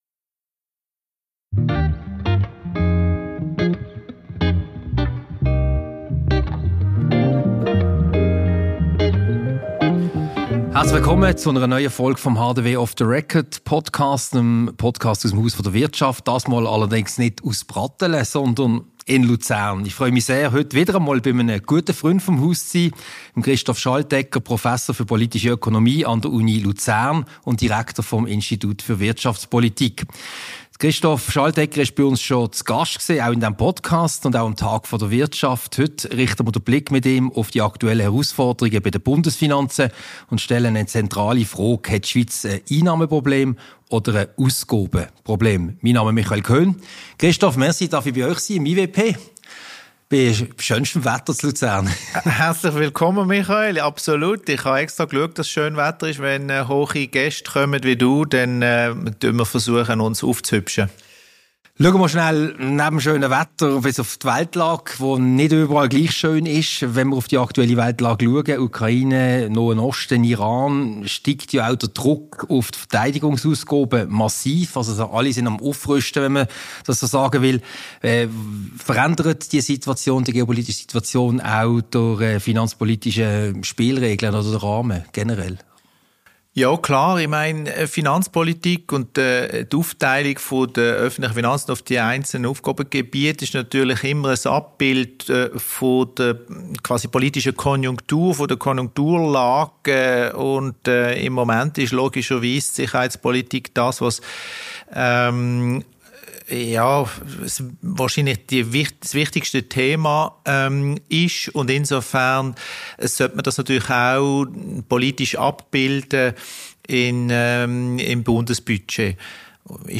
Ein Gespräch über die aktuellen Herausforderungen der Bundesfinanzen.